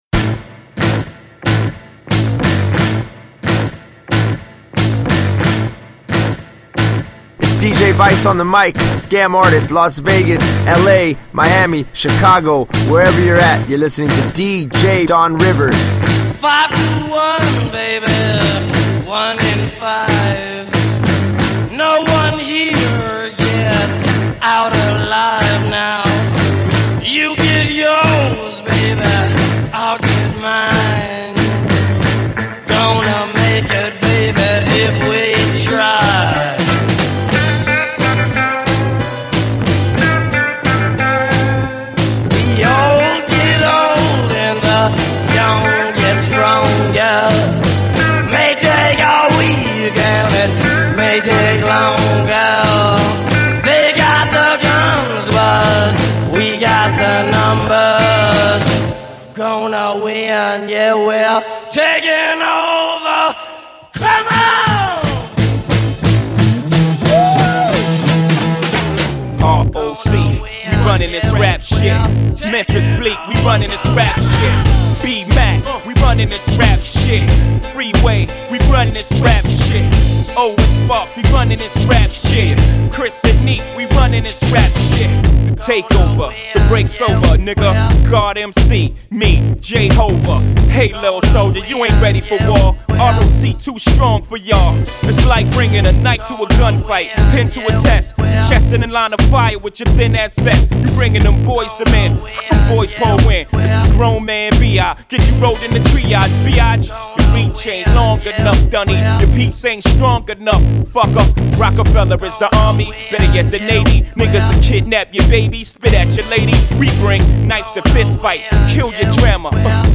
exclusive open format mix